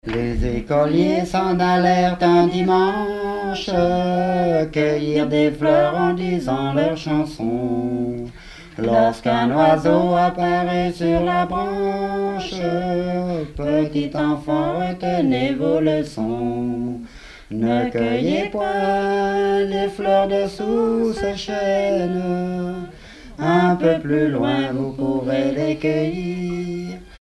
Bellevaux
Pièce musicale inédite